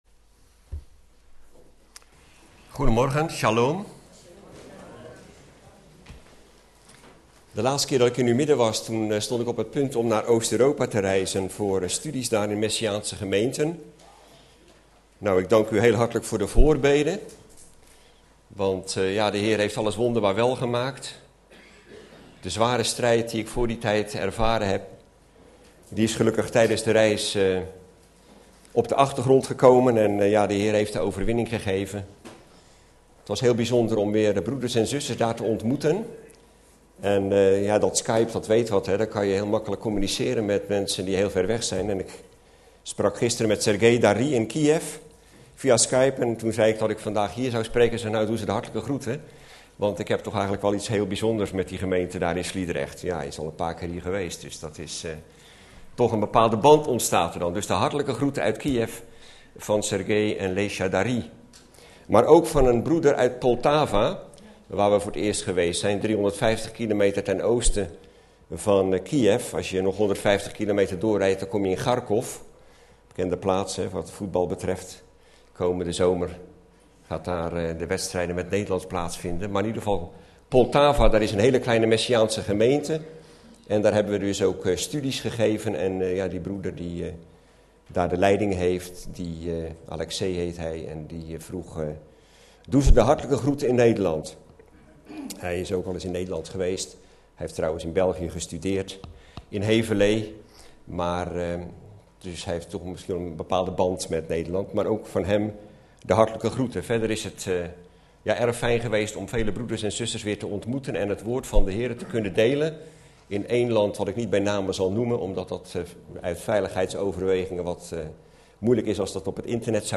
In de preek aangehaalde bijbelteksten (Statenvertaling)Genesis 3:1515 En Ik zal vijandschap zetten tussen u en tussen deze vrouw, en tussen uw zaad en tussen haar zaad; datzelve zal u den kop vermorzelen, en gij zult het de verzenen vermorzelen.